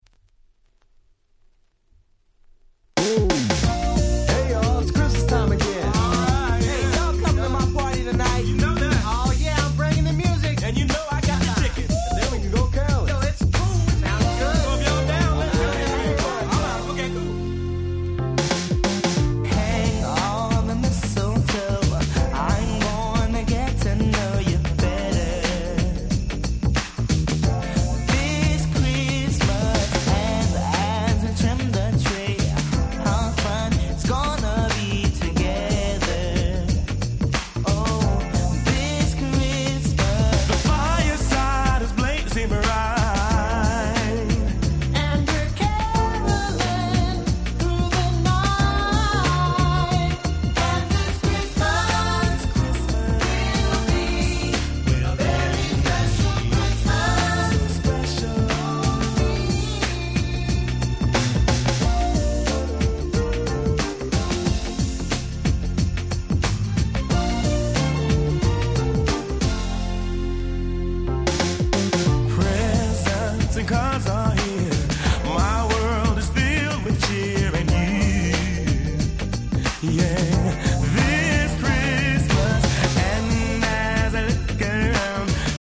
タイトル通り全曲クリスマスソング！！
音質もバッチリ！